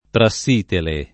[ pra SS& tele ]